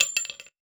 weapon_ammo_drop_08.wav